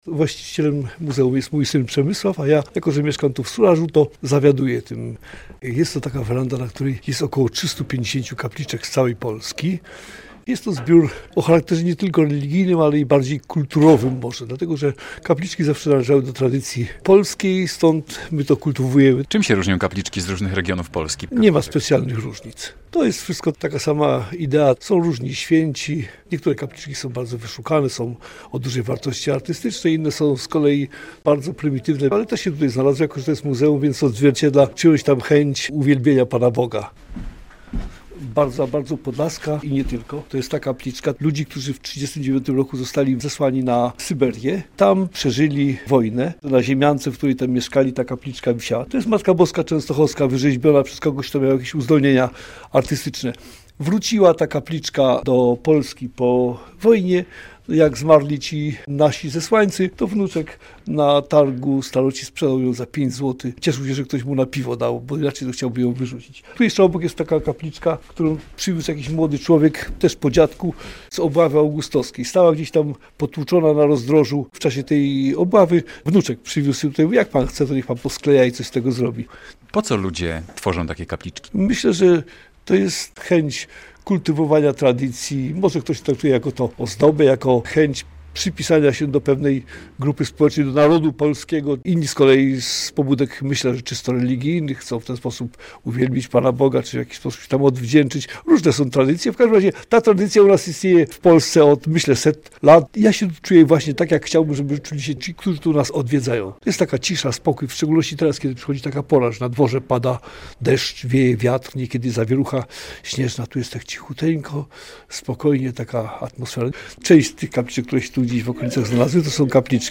Muzeum Kapliczek w Surażu - relacja